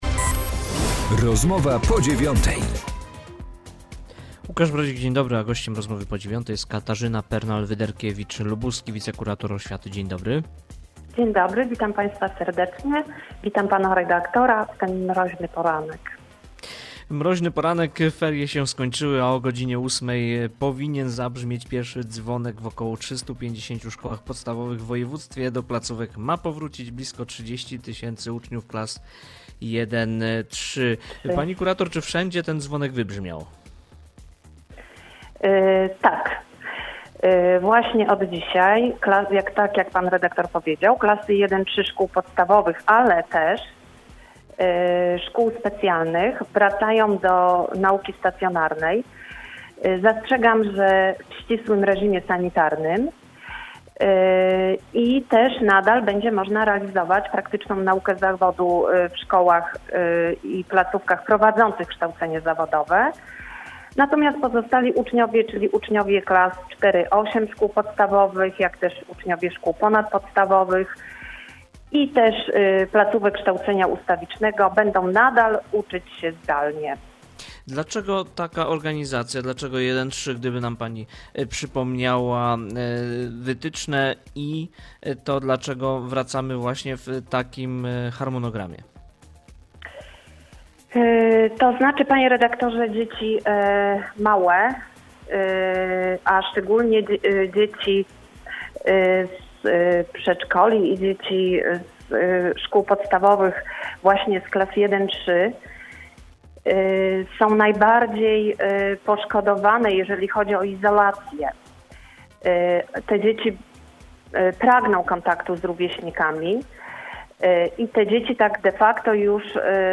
Z lubuską wicekurator oświaty rozmawia